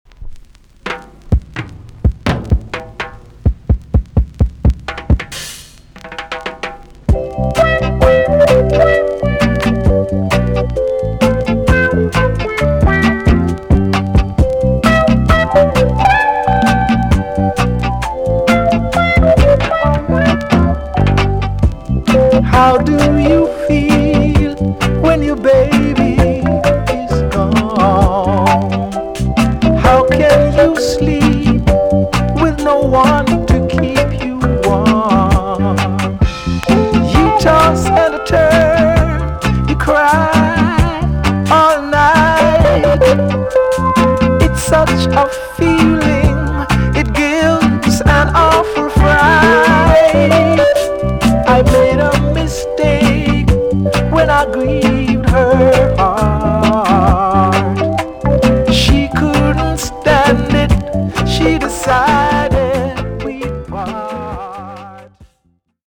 TOP >LP >VINTAGE , OLDIES , REGGAE
A.SIDE EX- 音はキレイです。